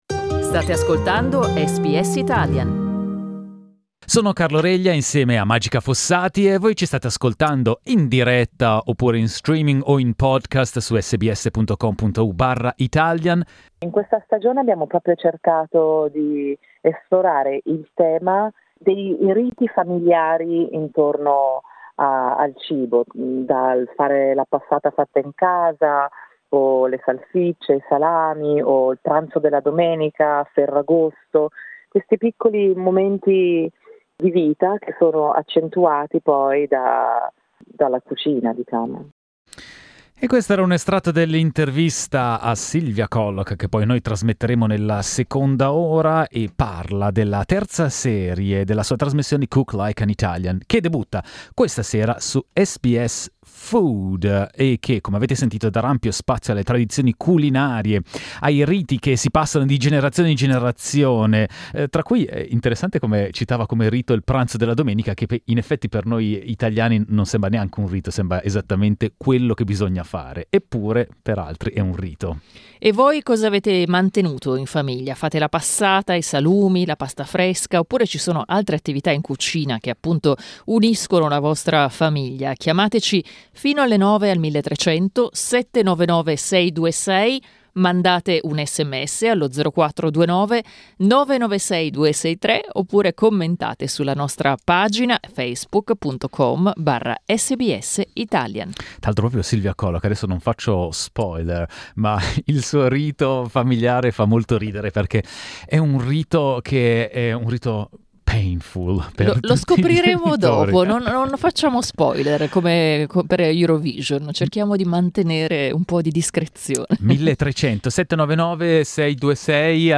Lo abbiamo chiesto ai nostri ascoltatori e alle nostre ascoltatrici.